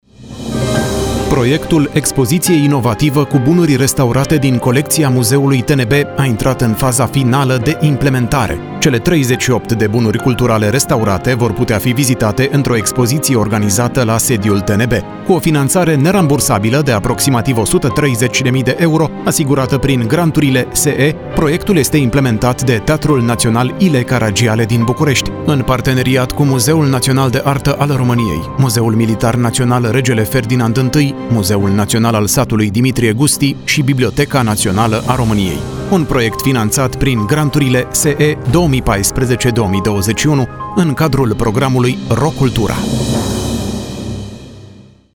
Spoturi promoționale difuzate la RFI Romania.